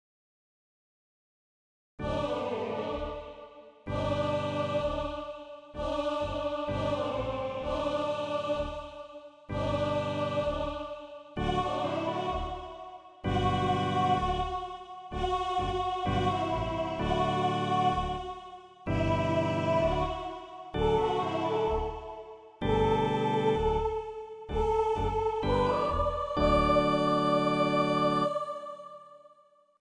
Audio synthétique de travail (sans paroles)